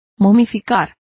Complete with pronunciation of the translation of mummifies.